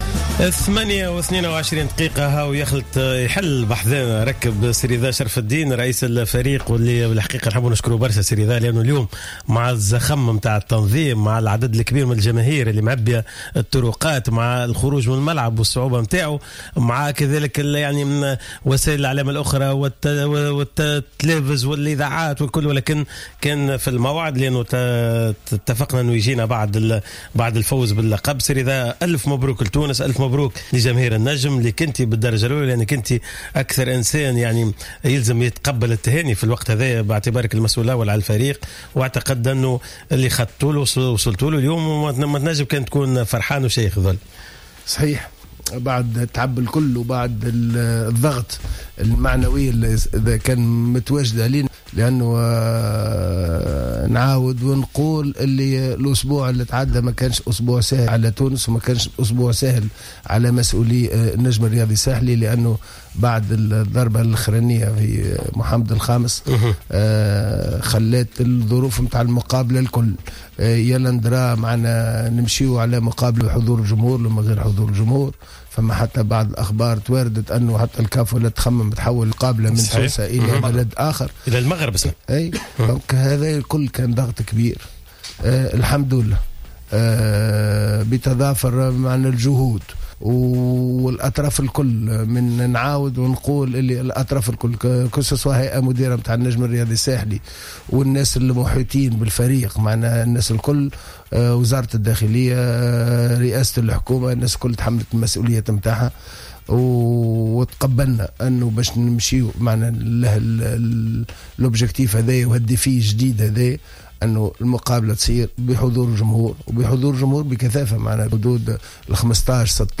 أكد رئيس النجم الرياضي الساحلي الذي حل ضيفا على راديو جوهرة أف أم إثر إحراز النجم على كأس الإتحاد الإفريقي لكرة القدم أن التتويج اليوم كان غاية في الروعة و مميزا إلى أبعد الحدود نظرا لعدة جوانب أهمها النجاح الجماهيري في مدارج الملعب بعد إلتزام الأحباء بالقواعد التنظيمية إضافة إلى رفع شعارات مناهضة للإرهاب و جميع أشكال التطرف كما ينضاف إلى ذلك النجاح الامني الكبير خاصة في ظل هذا الوضع الدقيق و الحساس الذي تمر به البلاد .